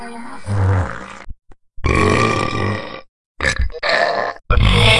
Проблемный пердёж
toilet4.wav